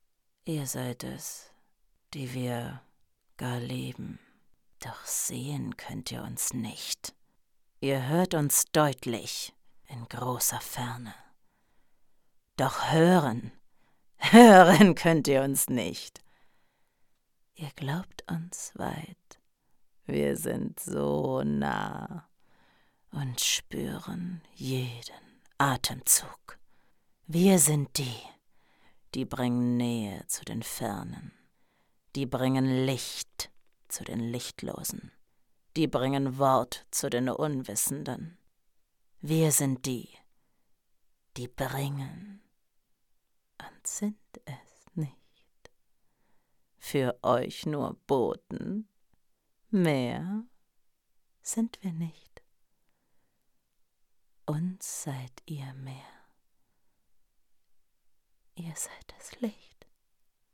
psycho download file >>